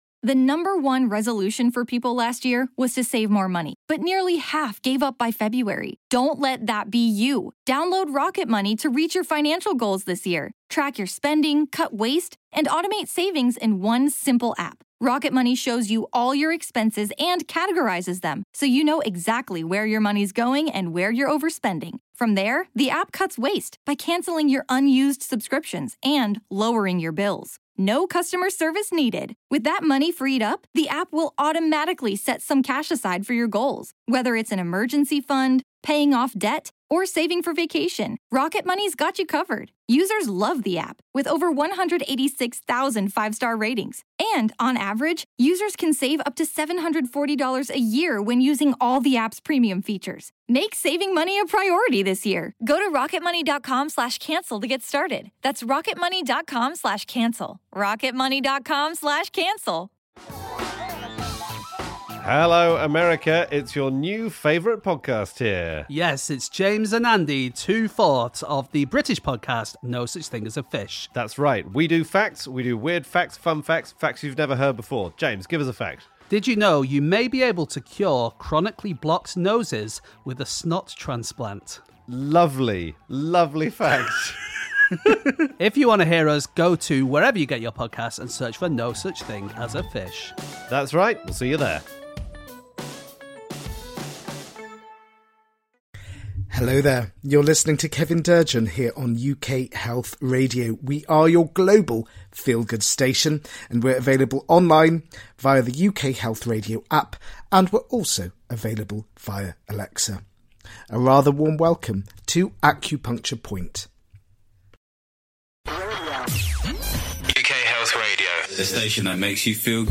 As he is an acupuncturist there will be plenty of Chinese medicine related content. He will also play some gorgeous music to uplift your soul and get your feet tapping with happiness.